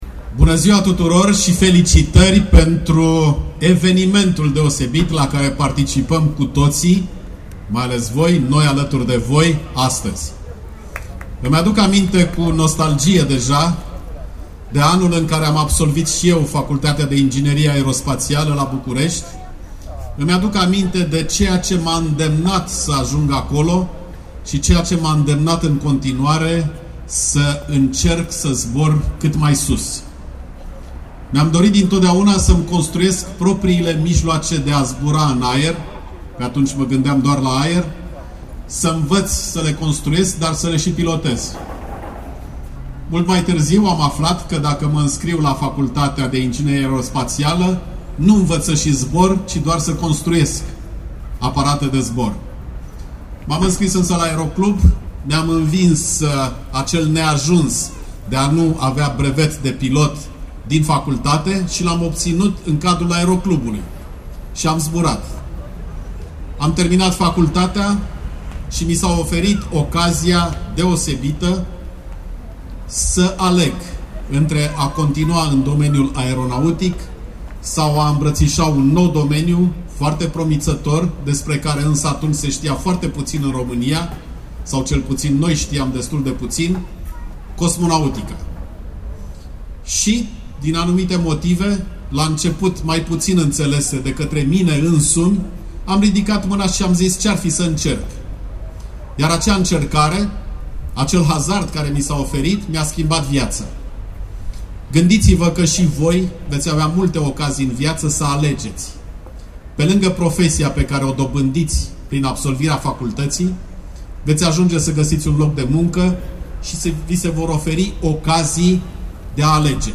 Dumitru Prunariu, discurs motivațional în fața studenților
Dumitru Prunariu, singurul român care a zburat în spaţiu, a fost prezent luni, 8 mai, la Universitatea „Ştefan cel Mare” din Suceava (USV), cu ocazia Marşului absolvenţilor.
discurs-Dumitru-Prunariu.mp3